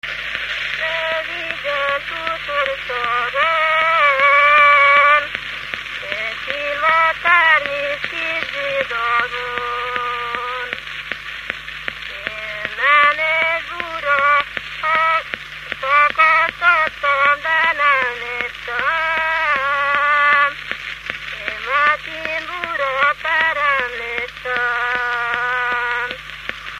Moldva és Bukovina - Moldva - Klézse
ének
Műfaj: Keserves
Stílus: 3. Pszalmodizáló stílusú dallamok
Szótagszám: 8.8.8.8
Kadencia: 5 (b3) 1 1